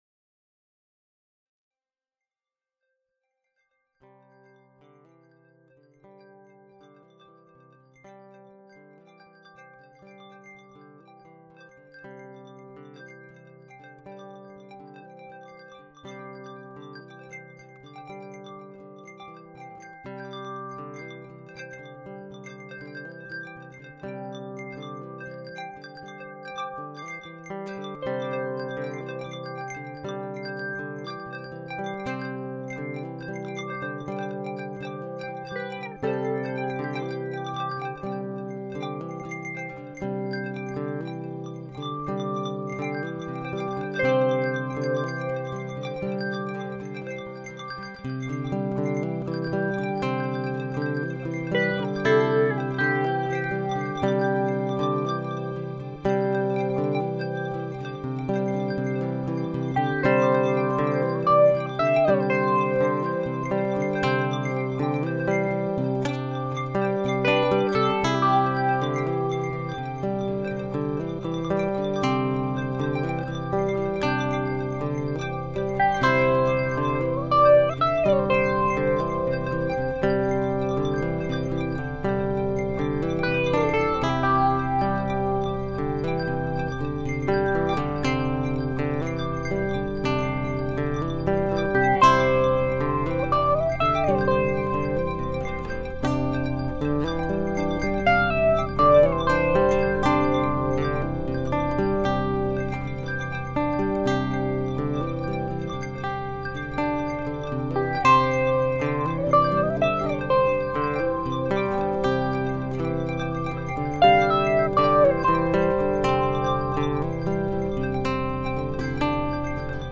versión instrumental